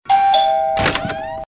• DOORBELL AND DOOR OPENING.mp3
A small apartment doorbell ringing, as the owner slowly opens a squeaking door.
doorbell_and_door_opening_fb4.wav